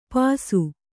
♪ pāsu